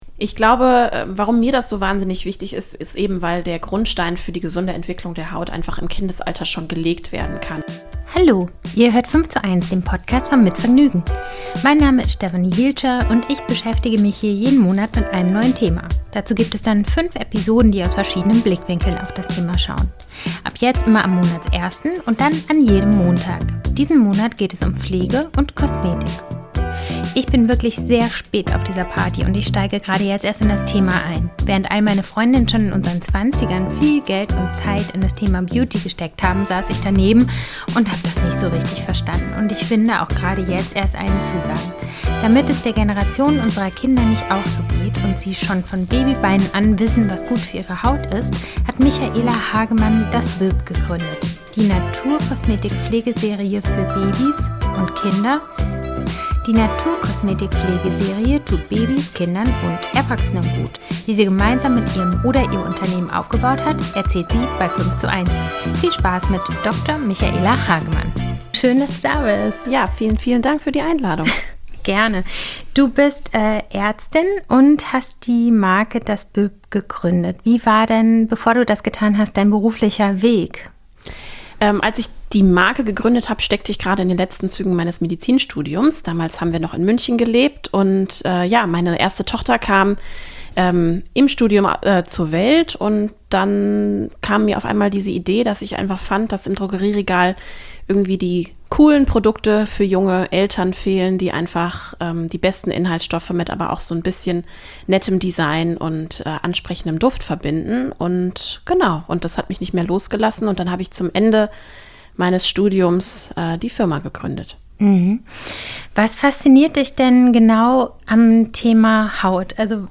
Ich spreche mit Moderatorinnen und Schauspielerinnen, mit Autorinnen und Unternehmerinnen